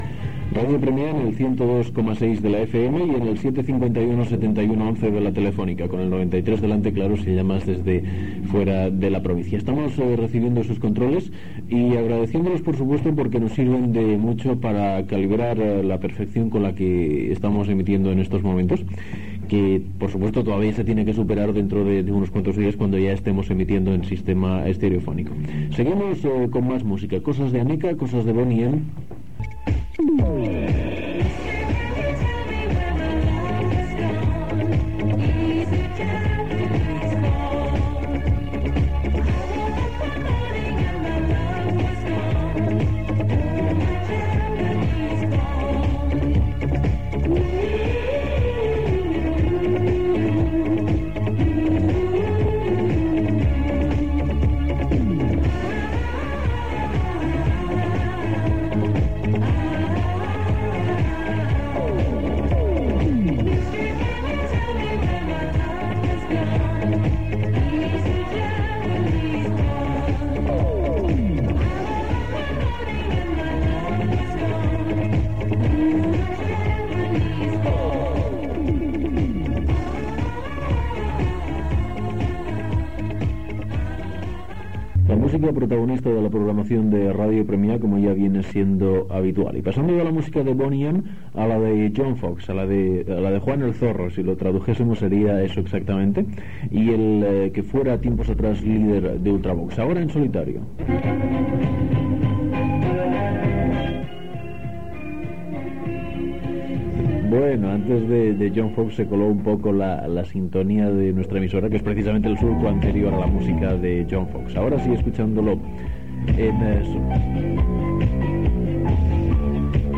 Programació musical amb identificació de l'emissora i telèfon.
Musical
FM